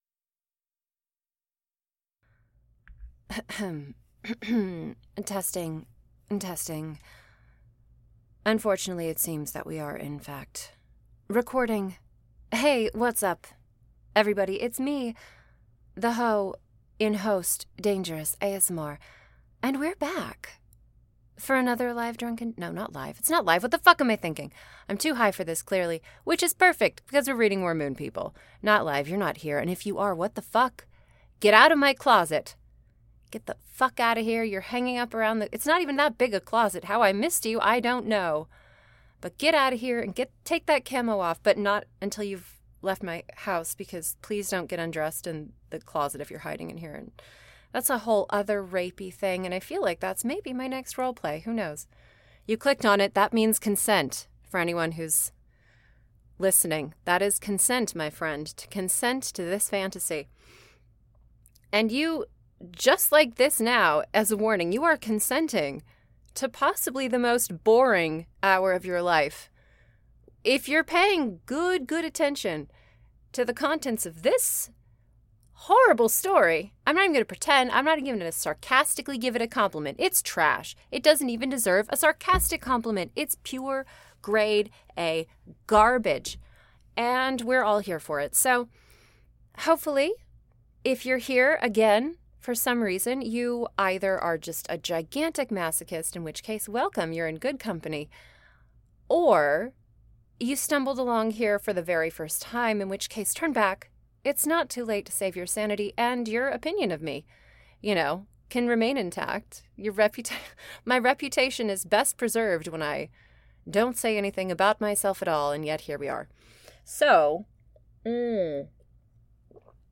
I hope you sickos enjoy today's patron-exclusive waste of time, the continuation of my dive into insanity as I drunkenly read more, Moon People, the worst story ever written by man or beast.